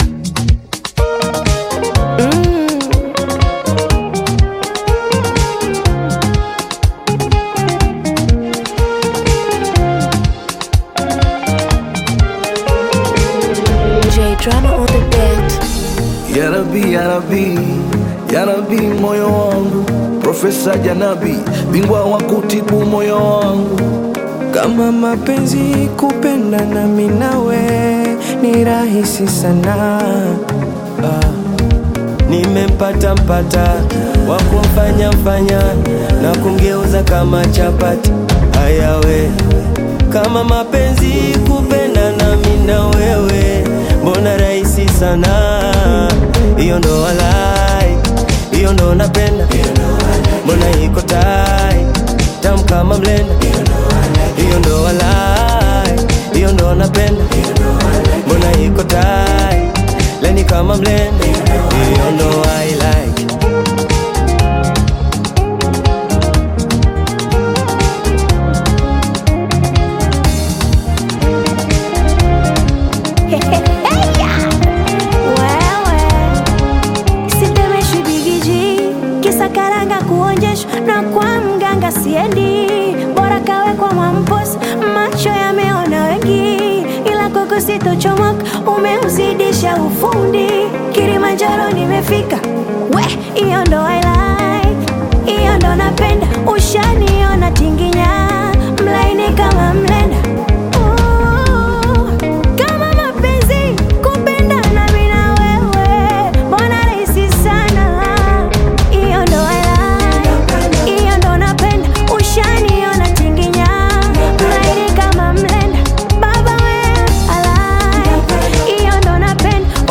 Bongo Flava music track